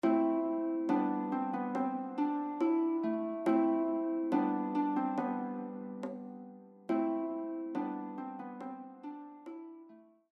This song is a traditional lullaby from High Cornwall.